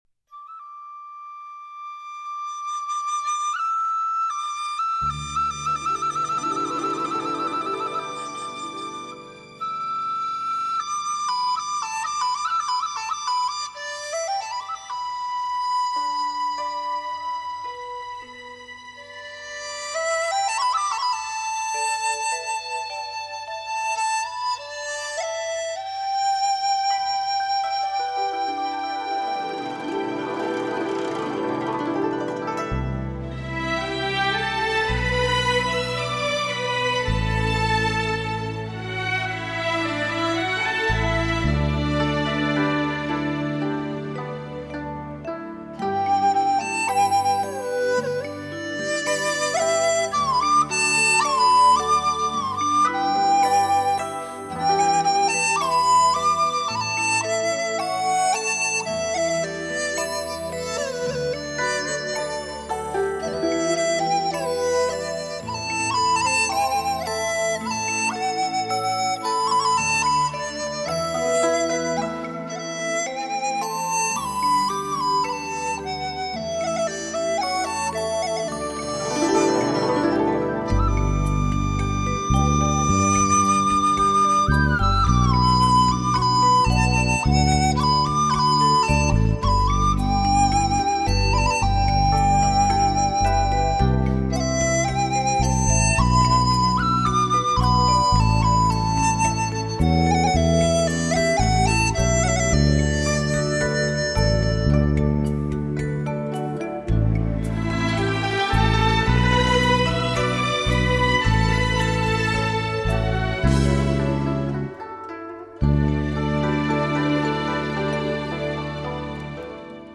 经“HDCD”技术处理，最明显在细致度、空间感及两极伸延都有极大的增强，相信“发烧友”听后，深信不疑！
乐队以中国民族乐器为主奏和领奏，辅以西洋管弦乐器及电声乐器。